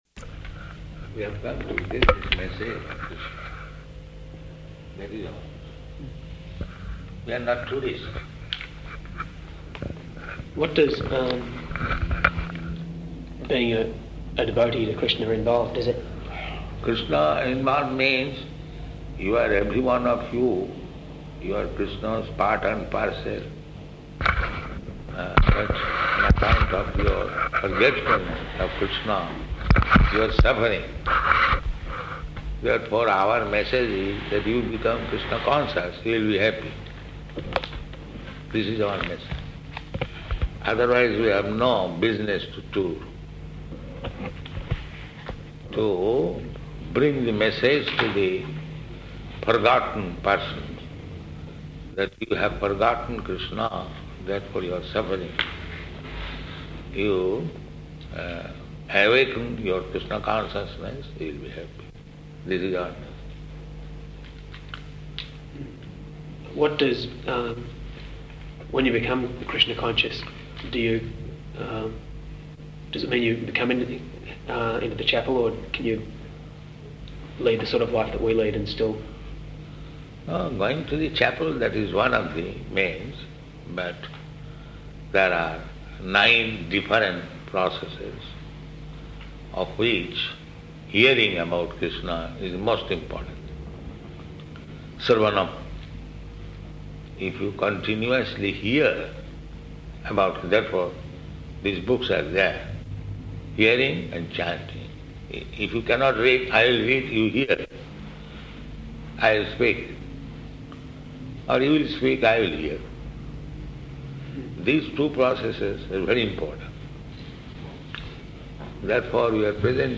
Room Conversation with Guests